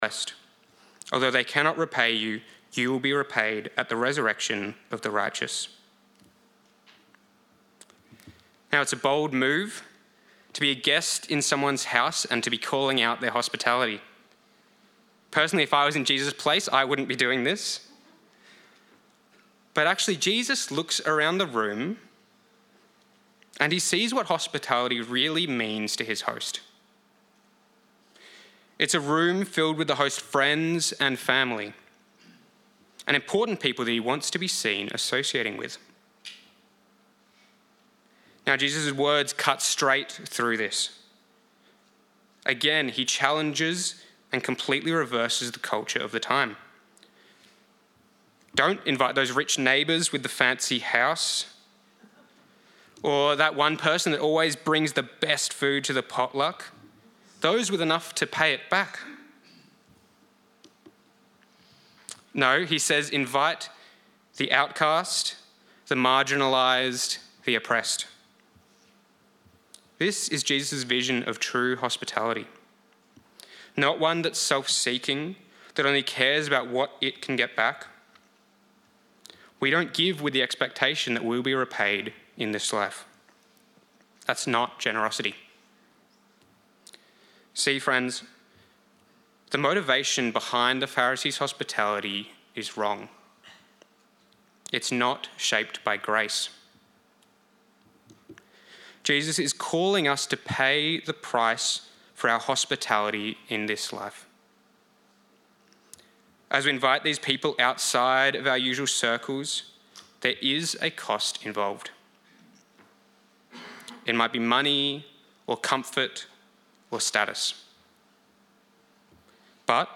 Guest Speaker
Download Download Bible Passage Luke 14:12–24 In this sermon